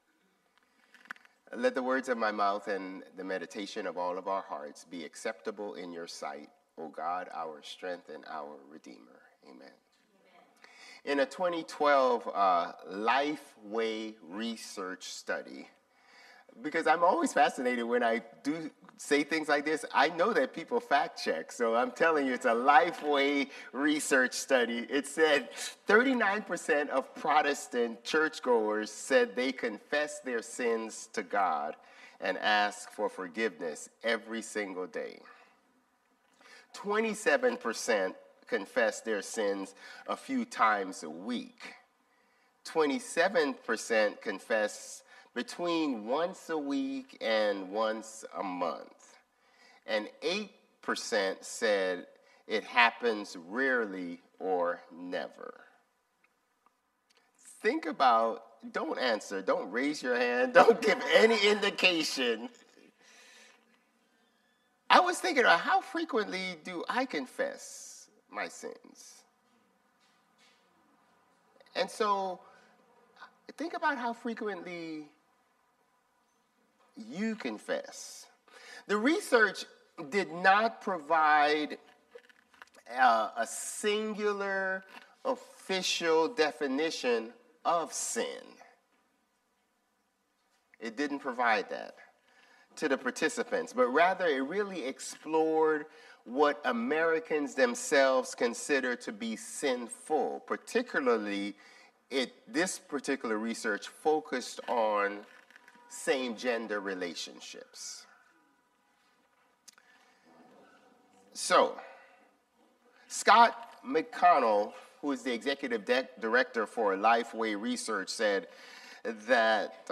Sermons | Bethel Lutheran Church
February 22 Worship